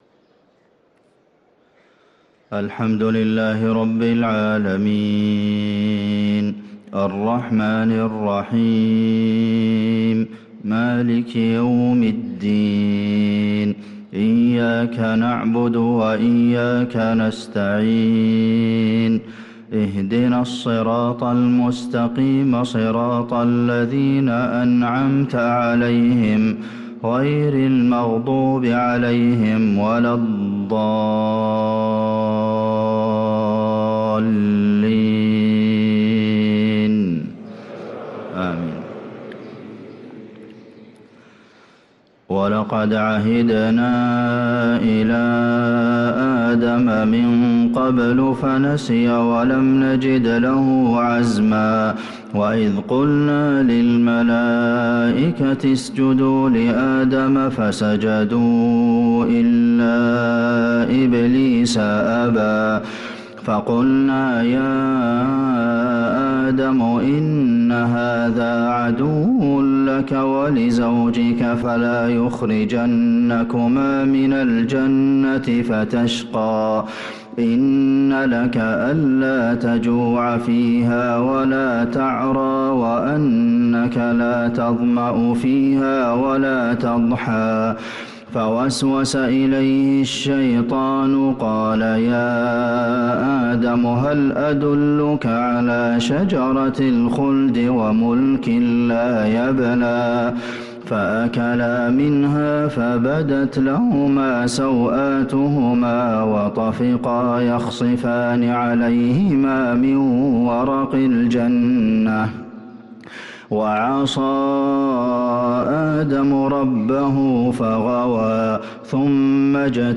صلاة الفجر للقارئ عبدالمحسن القاسم 27 رجب 1445 هـ
تِلَاوَات الْحَرَمَيْن .